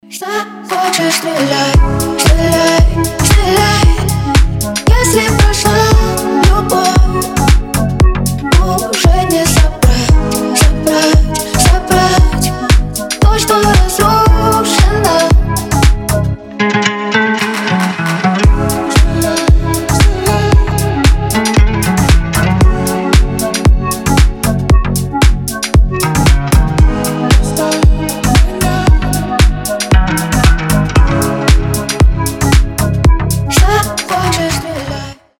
• Качество: 320, Stereo
deep house
чувственные